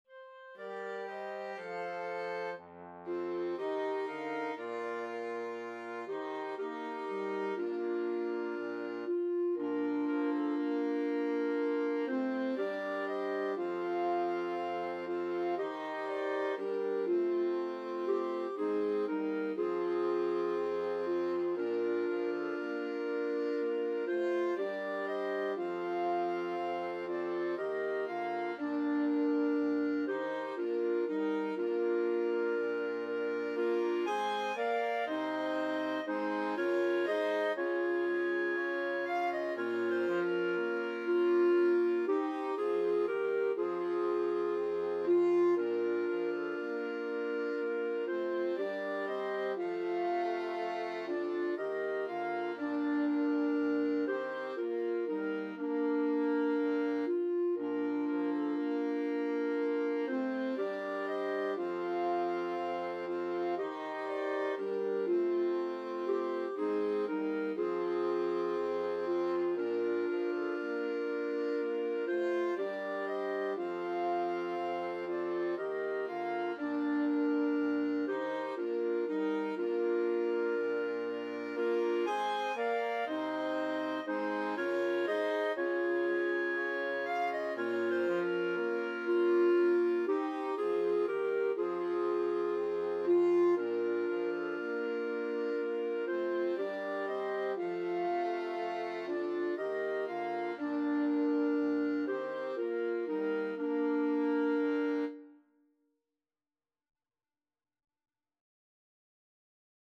Clarinet
Violin (Chords)
Trombone (Chords)
3/4 (View more 3/4 Music)
Slow, expressive =c.60